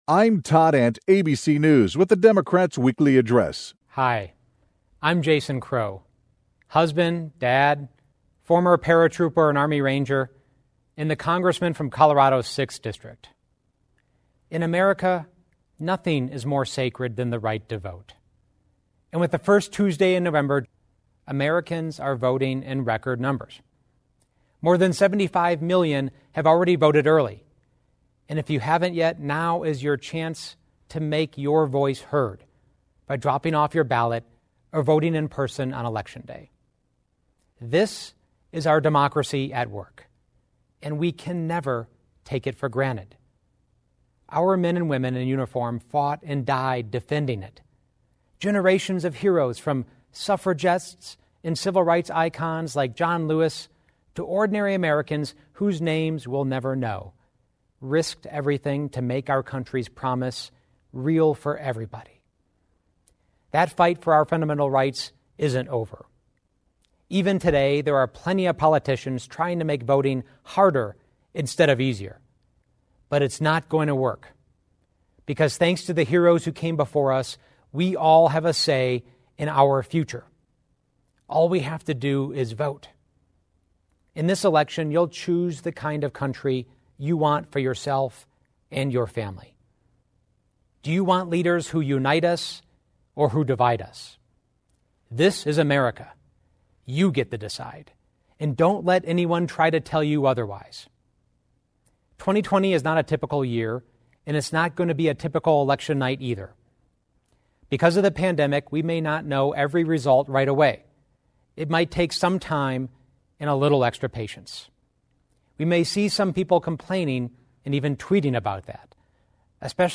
During the Democratic Weekly Address, Rep. Jason Crow (D-CO) stated that there are politicians trying to make voting harder, instead of easier.